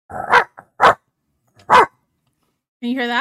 Kuku Barks